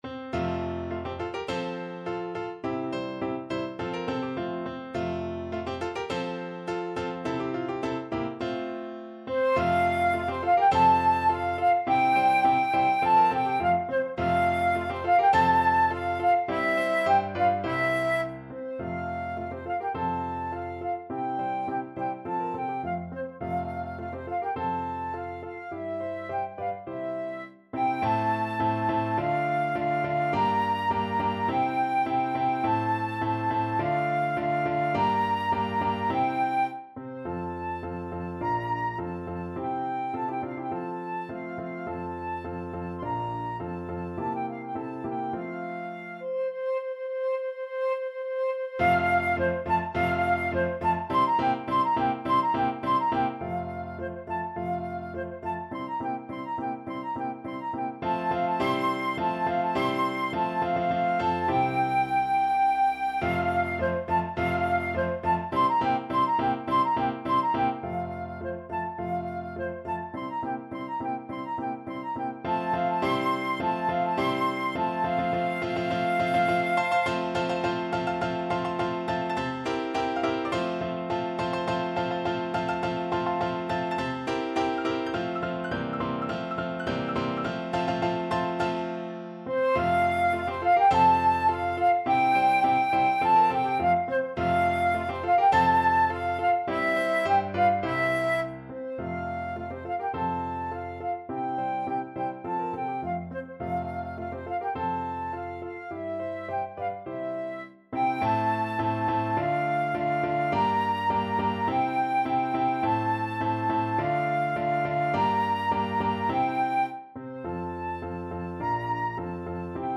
Free Sheet music for Flute
Flute
2/4 (View more 2/4 Music)
F major (Sounding Pitch) (View more F major Music for Flute )
~ = 100 Molto vivace =104
Classical (View more Classical Flute Music)